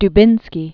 (d-bĭnskē), David 1892-1982.